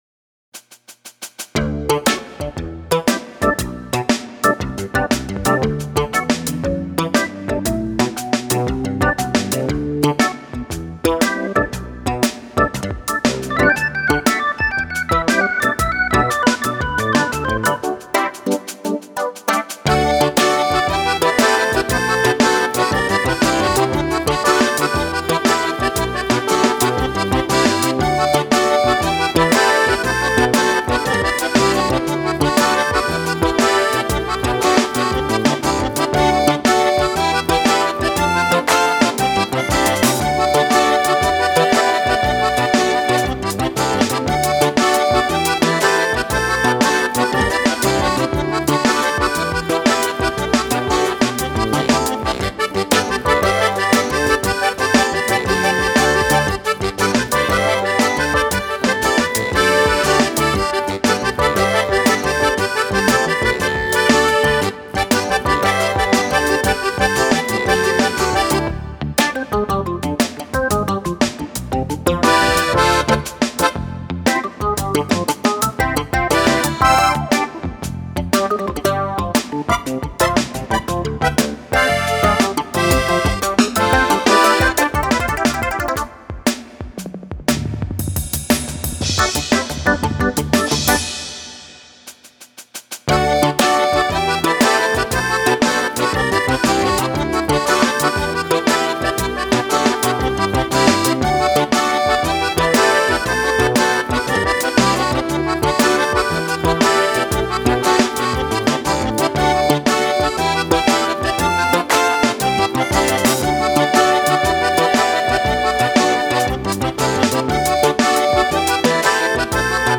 Genre: Contemporary Christian
Keyboards, Accordion, Guitar, Ukulele, Bass Guitar, Drums
Trumpet, Flumpet and all Brass Instruments